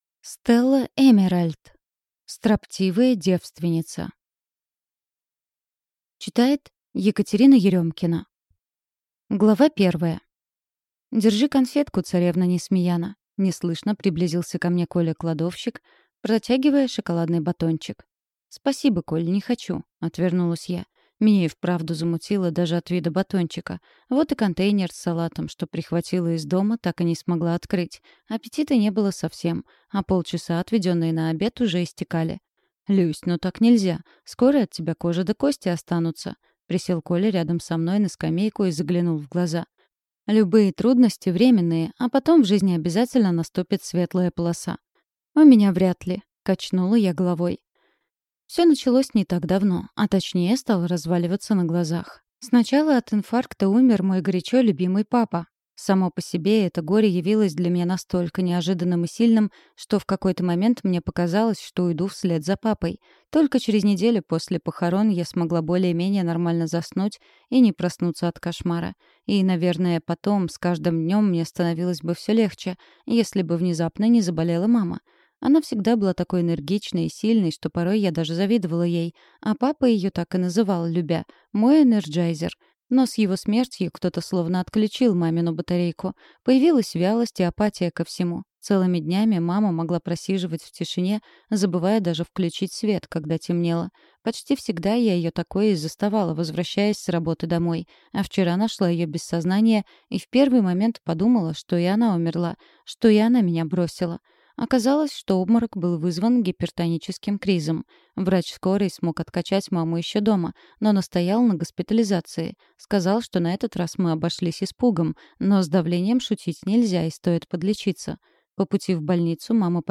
Аудиокнига Строптивая девственница | Библиотека аудиокниг
Прослушать и бесплатно скачать фрагмент аудиокниги